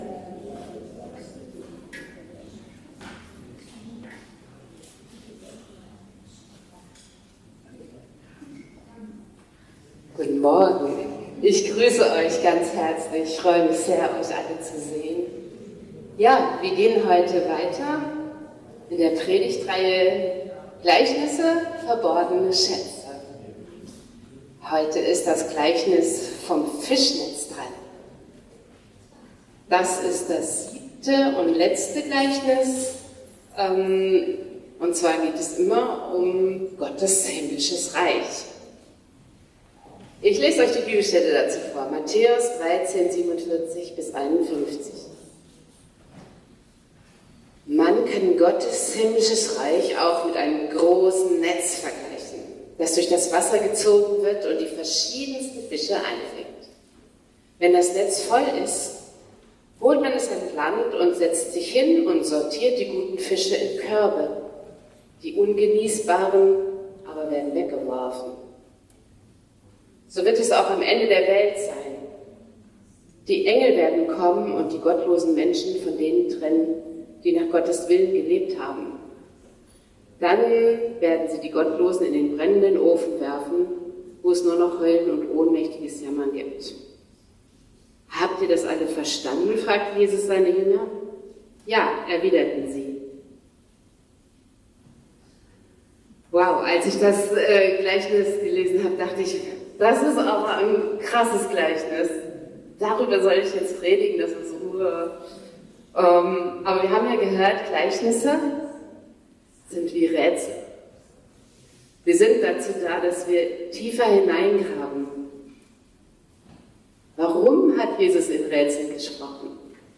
Predigtreihe: Gleichnisse – Verborgene Schätze